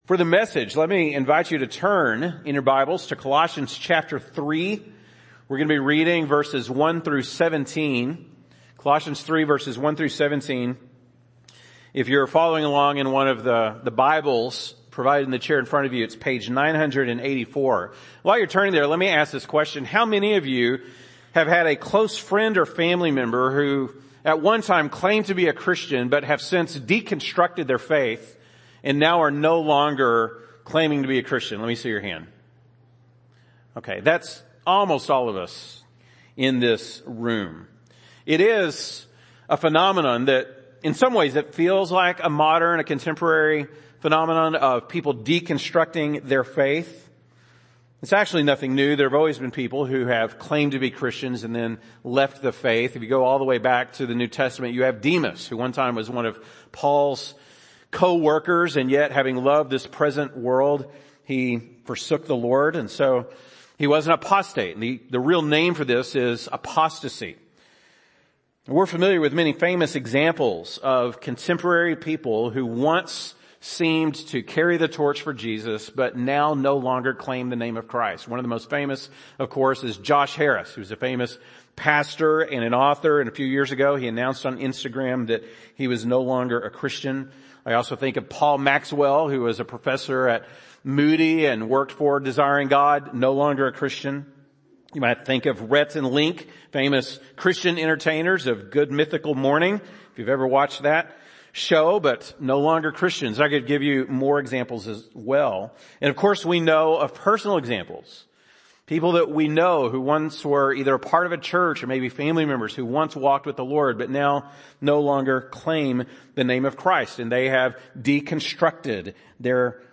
June 26, 2022 ( Sunday Morning )